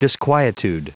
Prononciation du mot : disquietude
disquietude.wav